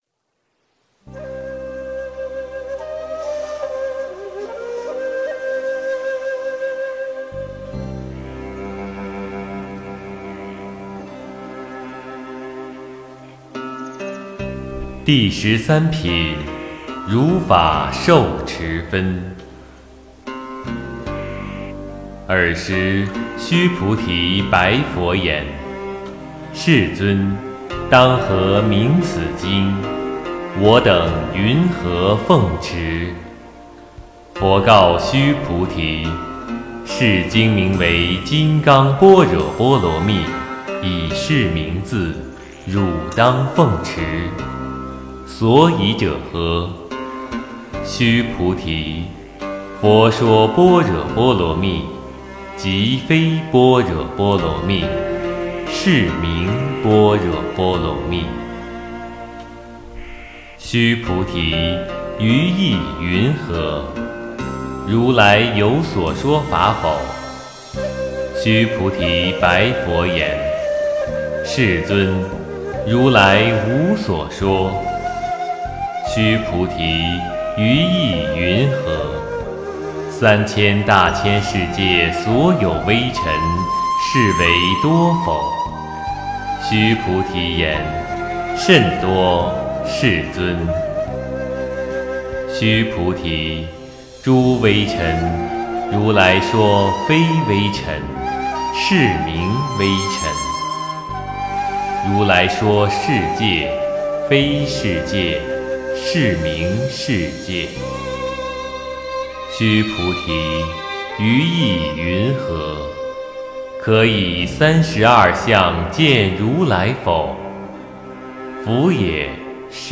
诵经
佛音 诵经 佛教音乐 返回列表 上一篇： 金刚经：第九品和第十品 下一篇： 金刚经：第二十三品和第二十四品 相关文章 Felicidad--The Buddhist Monks Felicidad--The Buddhist Monks...